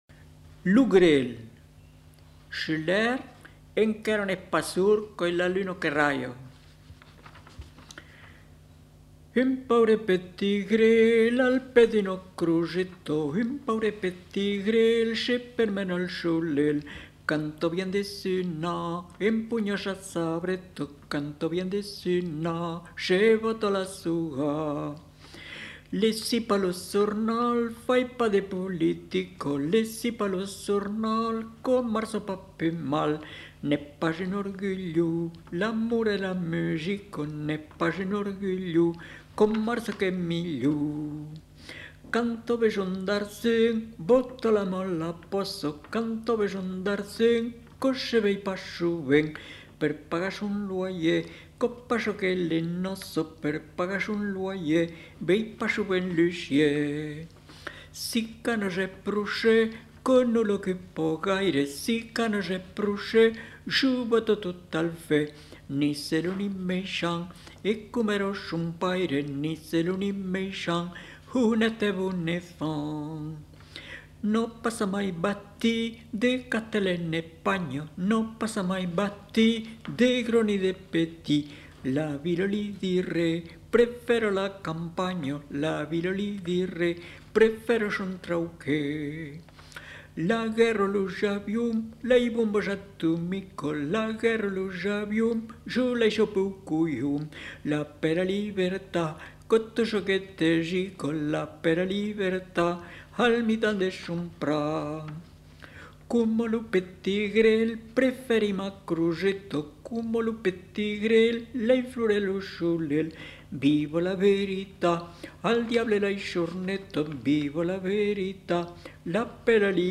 Aire culturelle : Périgord
Lieu : Lolme
Genre : chant
Effectif : 1
Type de voix : voix d'homme
Production du son : chanté